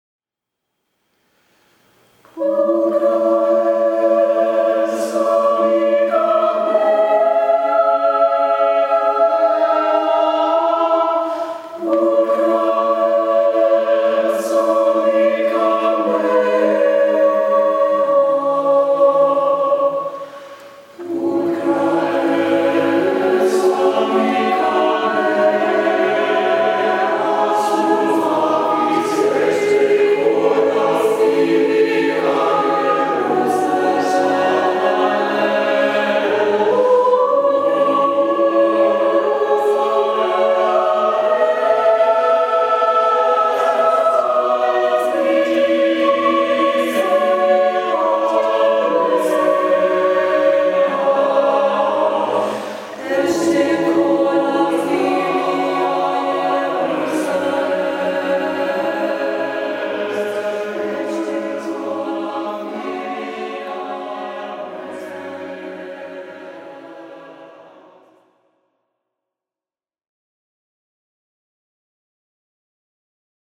Looking Back, Looking Ahead, Looking Inward: Thirty seasons of choral song
Samples of the choir from this program!